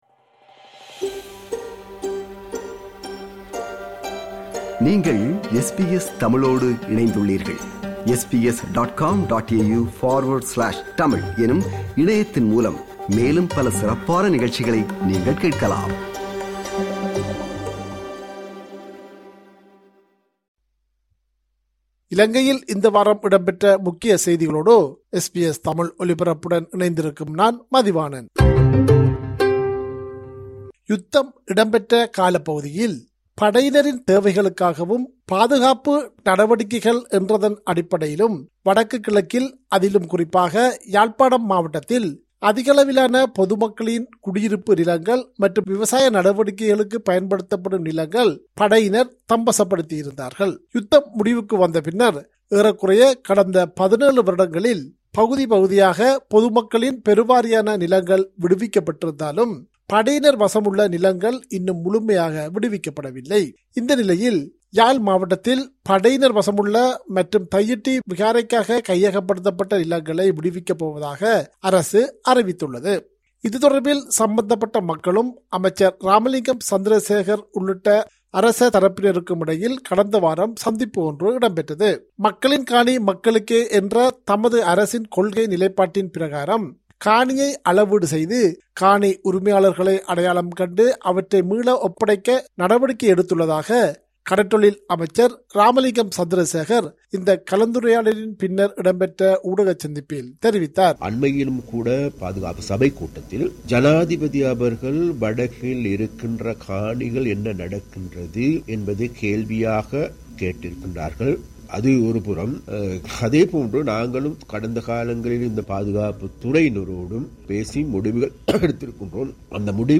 Top news from Sri Lanka this week